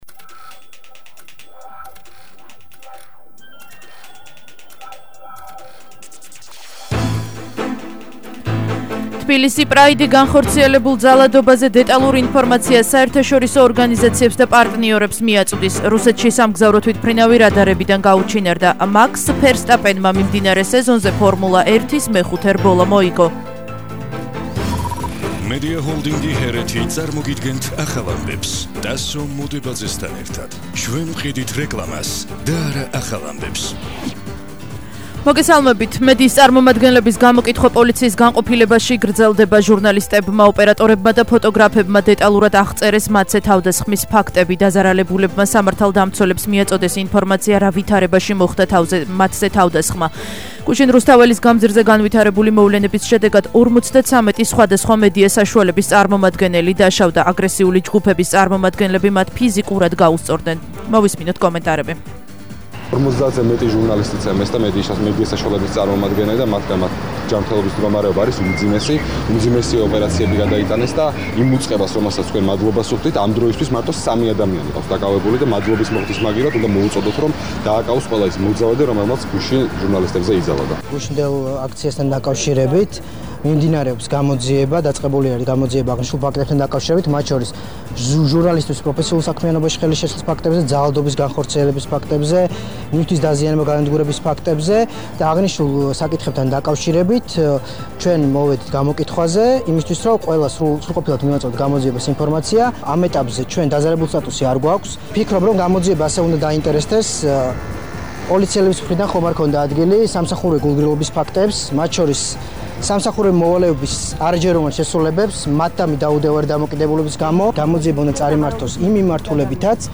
ახალი ამბები 13:00 საათზე –06/07/21 - HeretiFM